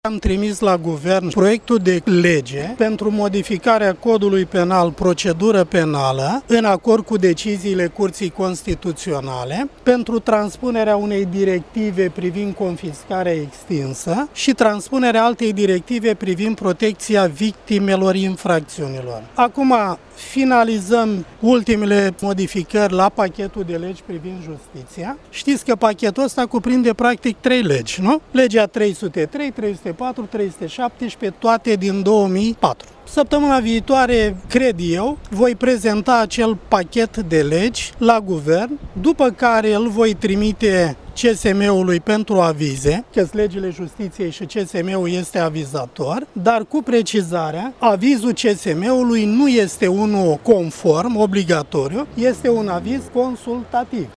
Ministrul justiţiei Tudorle Toader a mai declarat astăzi, la Iaşi, că modificările la legile justiţiei vor fi prezentate la Guvern, cel mai probabil, săptămâna viitoare,